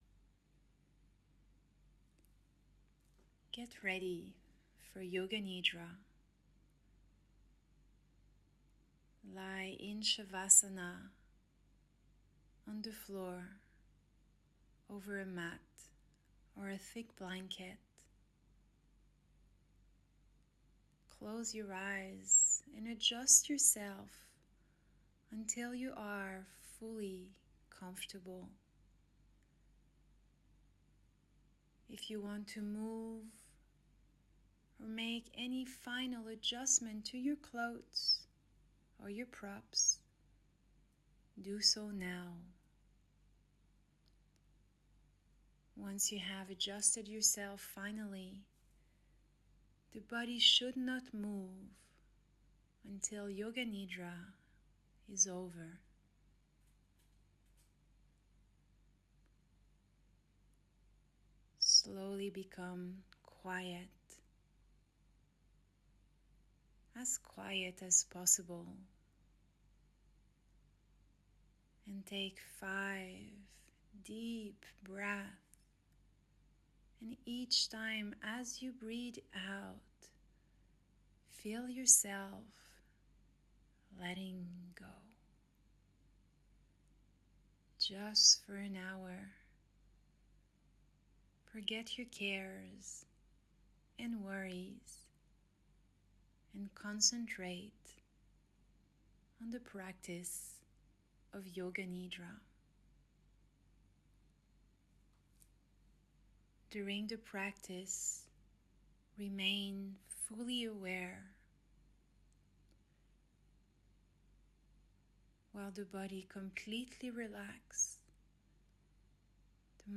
In Yoga Nidra, one learns to induce his own state of relaxation by following the spoken instructions.